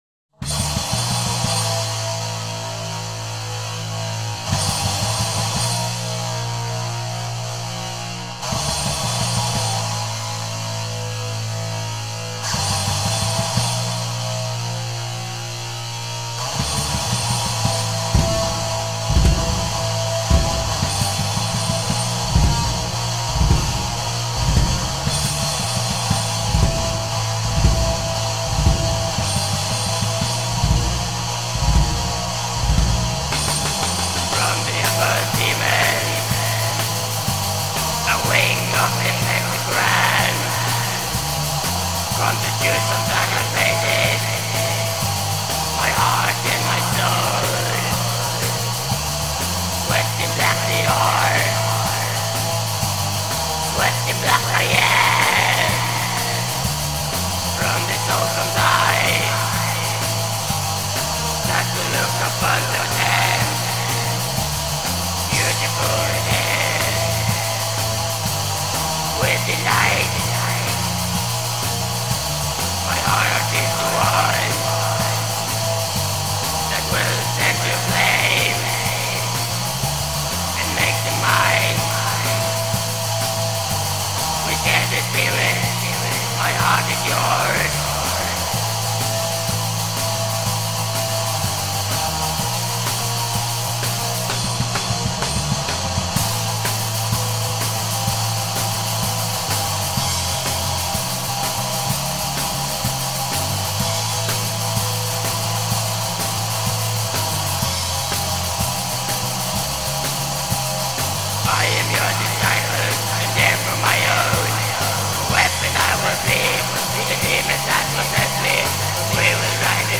بلک متال